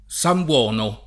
[ S am b U0 no ]